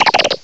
cry_not_clauncher.aif